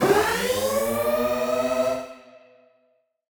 Index of /musicradar/future-rave-samples/Siren-Horn Type Hits/Ramp Up
FR_SirHornA[up]-C.wav